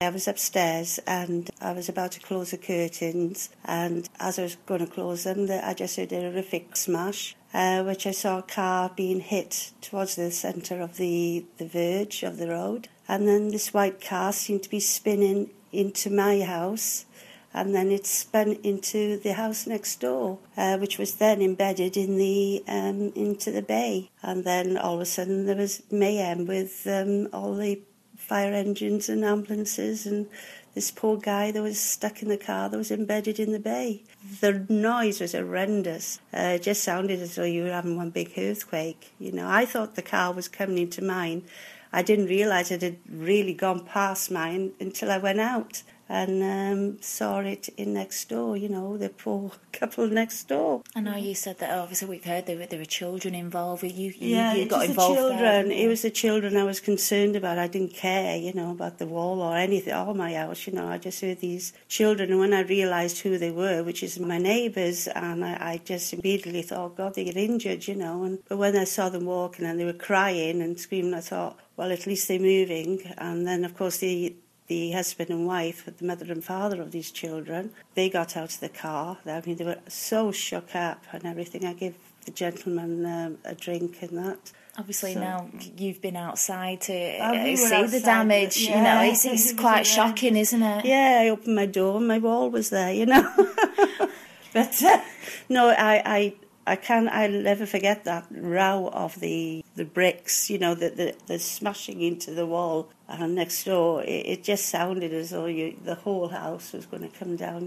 Neighbour speaks of the moment a car crashed into next door's home.